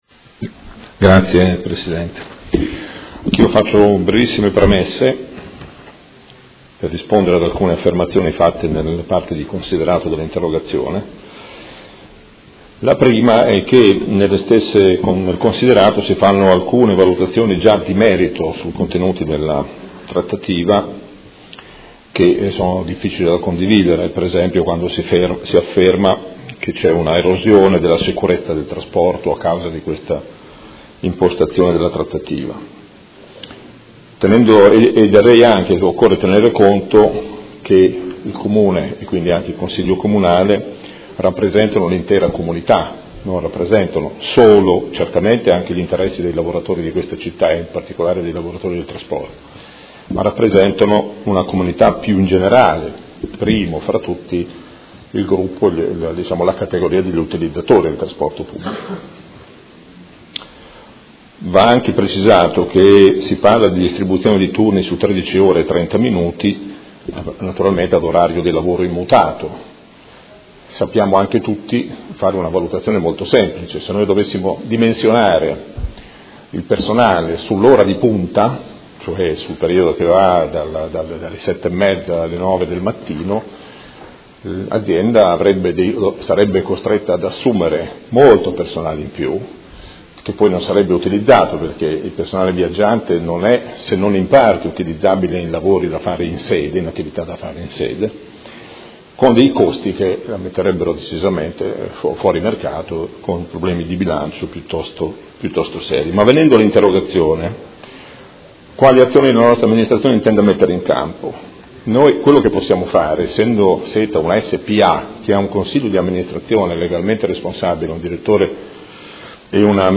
Seduta del 20/04/2016. Risponde a interrogazione del Consigliere Cugusi (SEL), Chincarini e Campana (Per Me Modena) e Rocco (FAS – Sinistra italiana) avente per oggetto: Quale futuro per il trasporto pubblico locale?